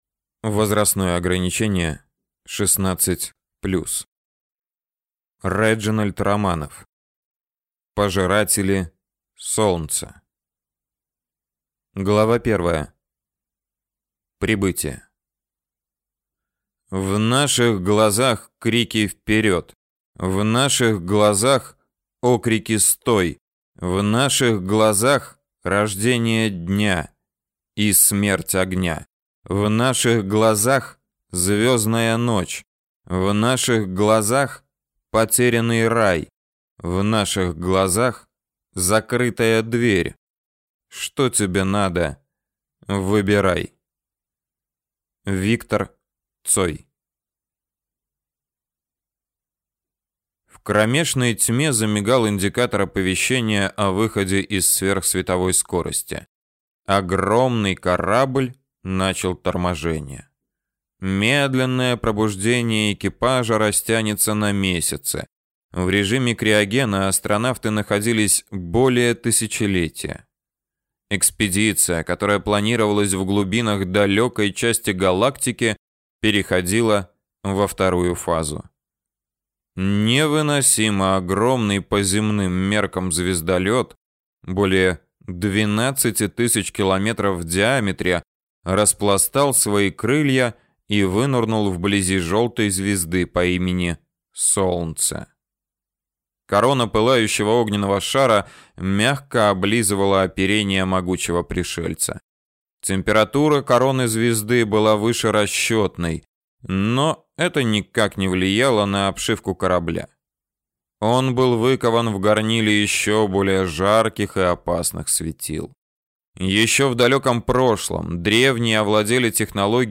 Аудиокнига Пожиратели Солнца | Библиотека аудиокниг